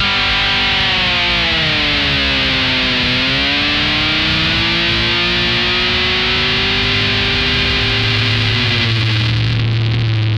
Index of /90_sSampleCDs/Zero G - Funk Guitar/Partition I/VOLUME 001